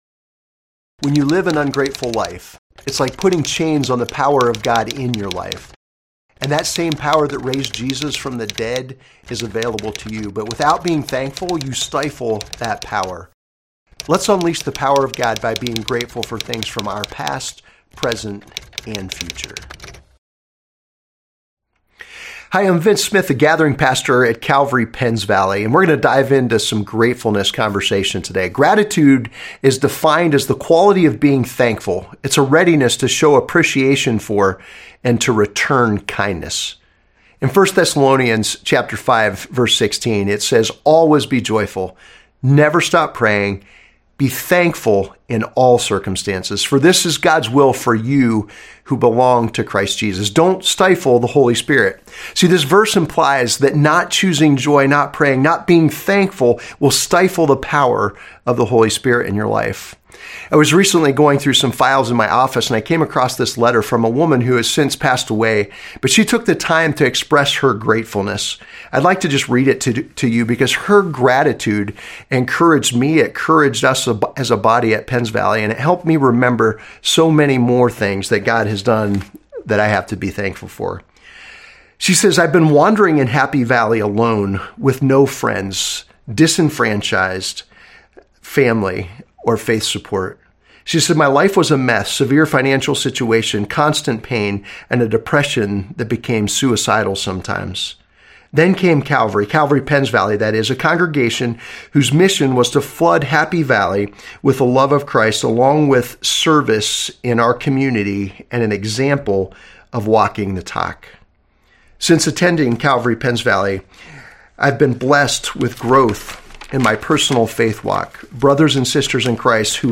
This is a three part sermon on Gratitude: